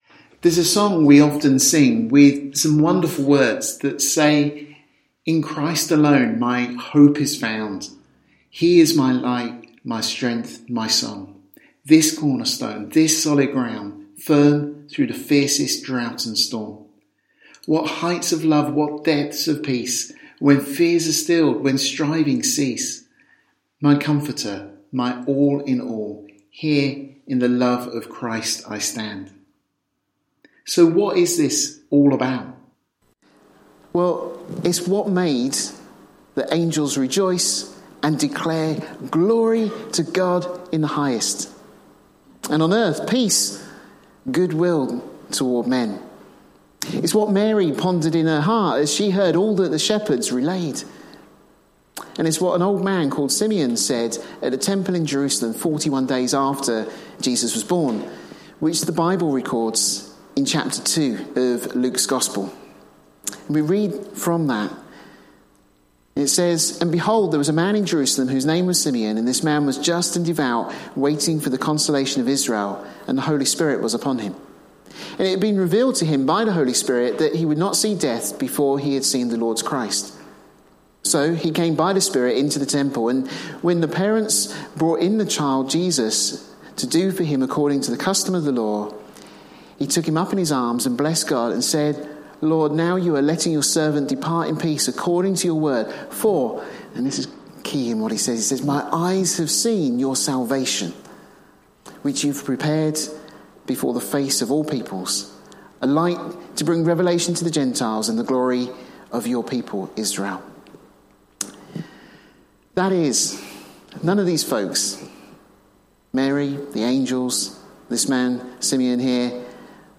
Topical Bible studies